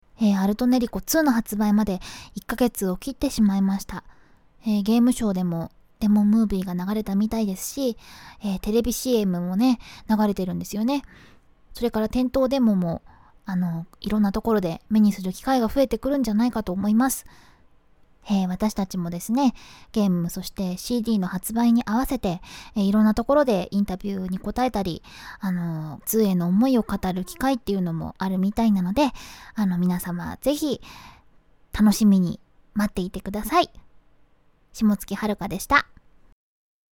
歌い手さんには、霜月はるかさんにインタビューをさせていただき ました。
▼霜月さんからのメッセージ